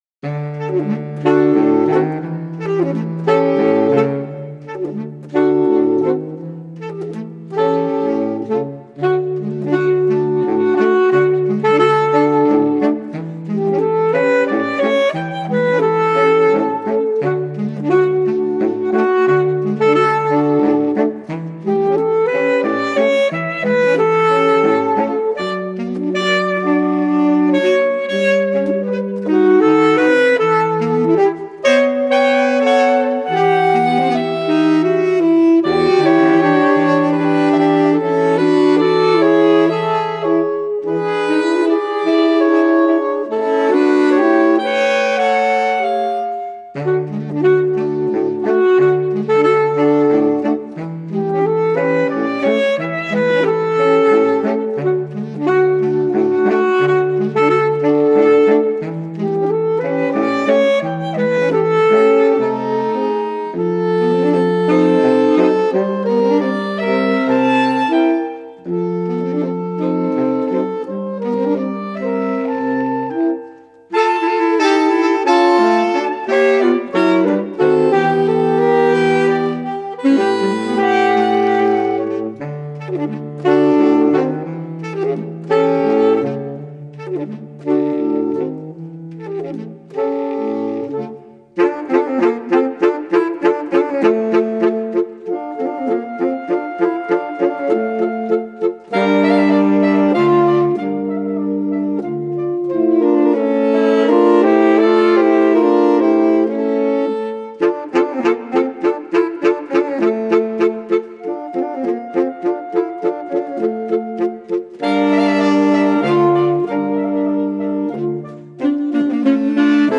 Echele Varielle - Das Saxophonquartett aus Nürnberg
Echelle Varielle - das Saxophon Quartett aus Nürnberg.